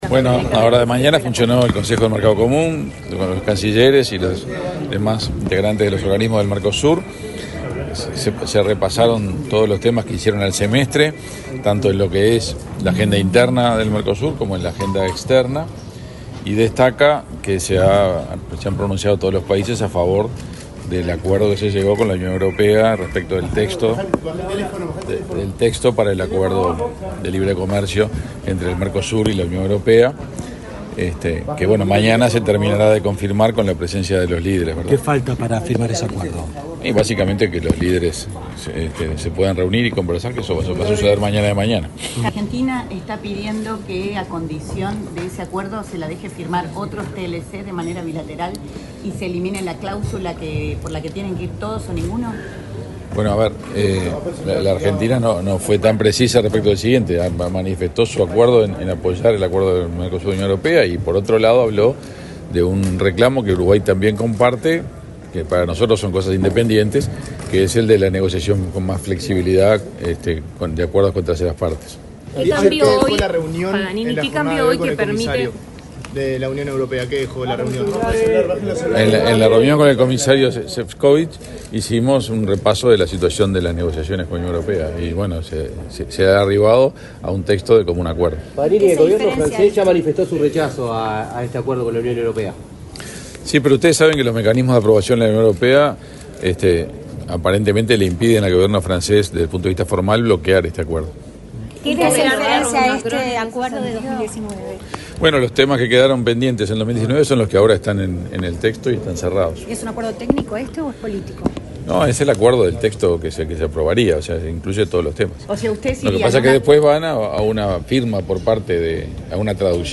Declaraciones del canciller Omar Paganini
El canciller Omar Paganini dialogó con la prensa, este jueves 5, luego de la LXV reunión ordinaria del Consejo del Mercado Común.